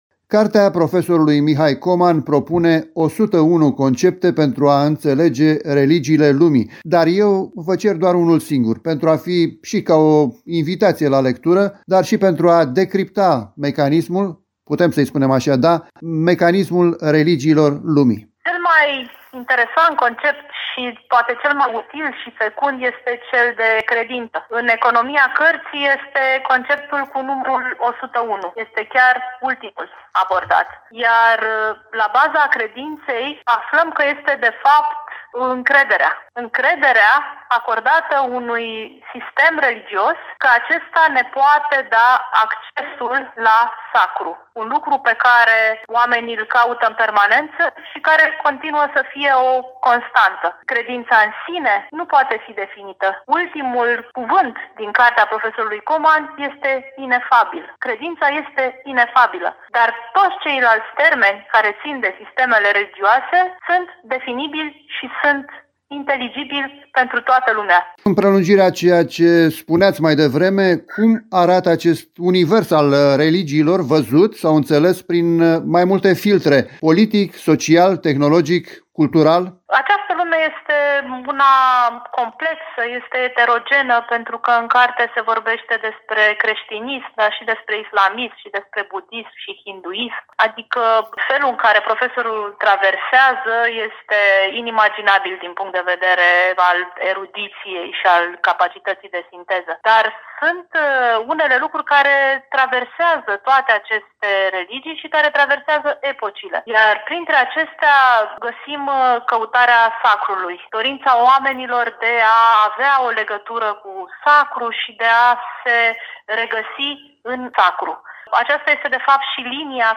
Detalii despre eveniment ne oferă